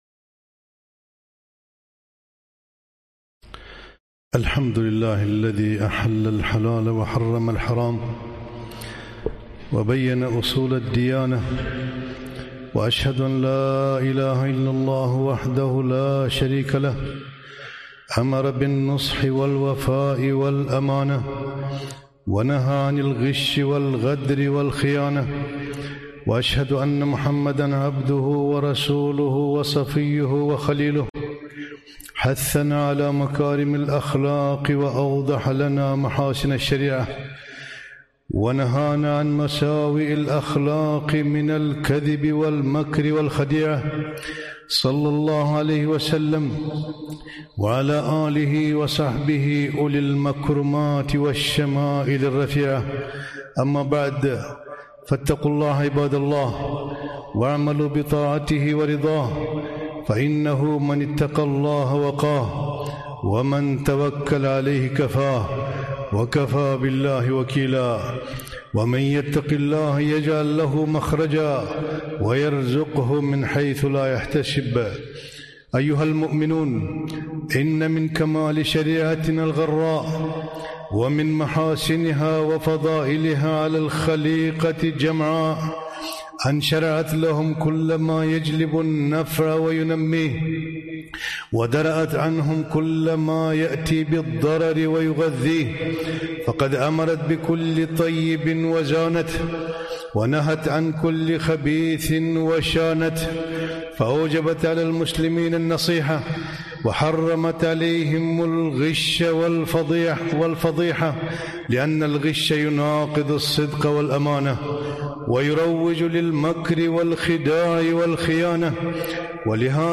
خطبة - حرمة الغش والخديعة